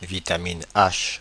Ääntäminen
Synonyymit vitamine B8 biotine Ääntäminen France (Paris): IPA: /vi.ta.min be ʔaʃ/ Haettu sana löytyi näillä lähdekielillä: ranska Käännöksiä ei löytynyt valitulle kohdekielelle.